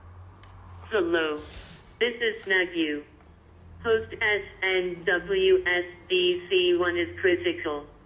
smseagle-voice-message.mp3